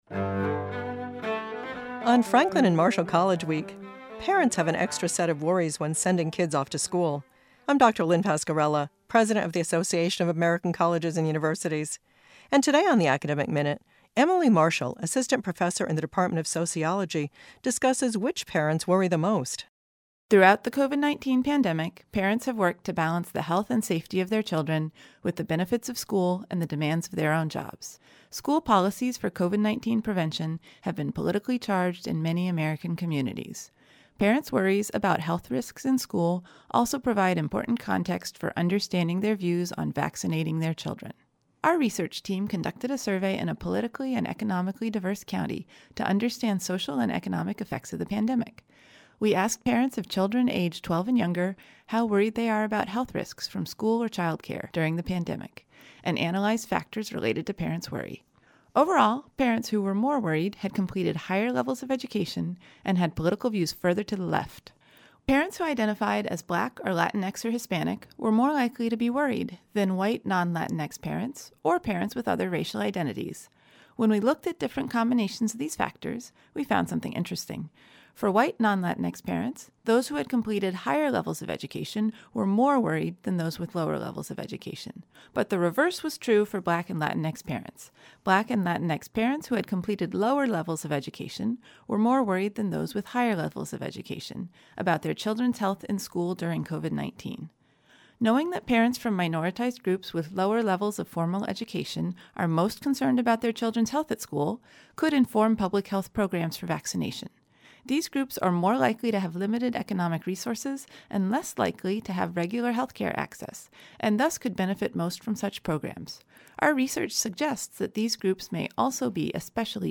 assistant professor in the department of sociology, discusses which parents worry the most.